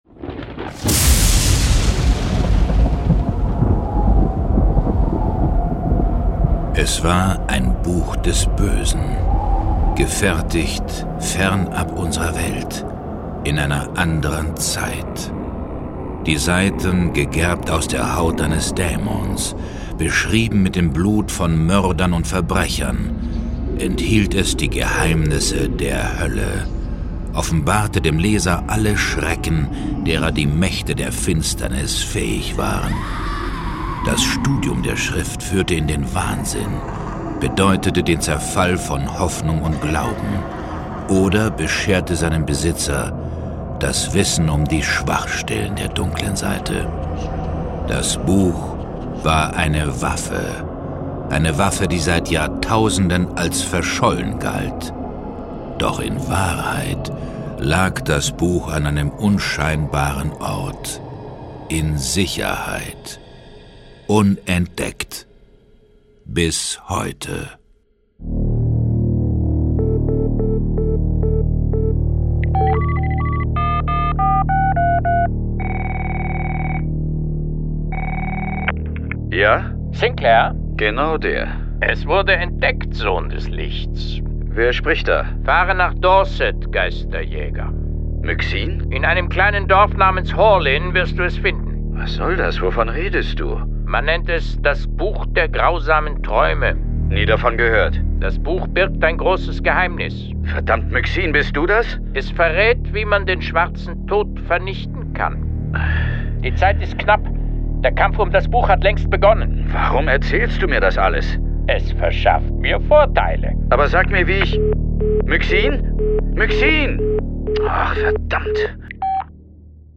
John Sinclair - Folge 20 Das Buch der grausamen Träume. Hörspiel.